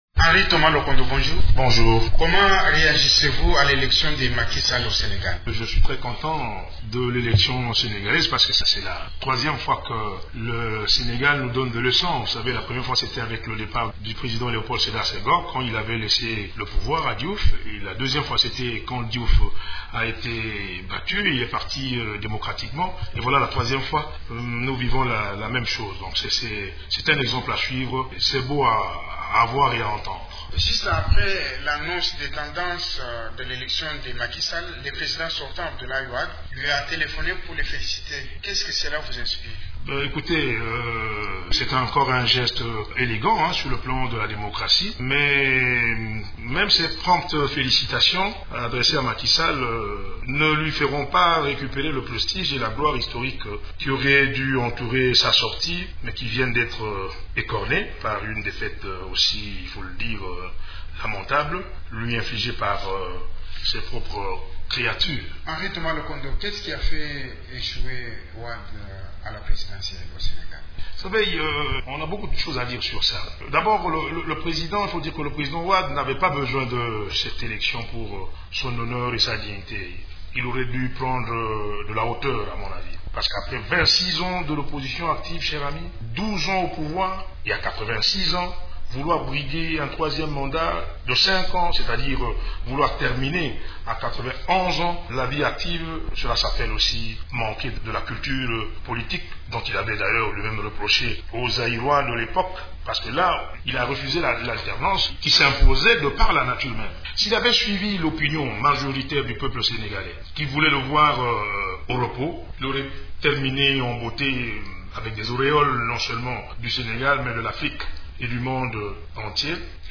Henri-Thomas Lokondo, président de l’Union Congolaise pour la Liberté(UCL), au studio de Radio Okapi.